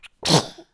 CosmicRageSounds / wav / general / baby / sneeze2.wav
sneeze2.wav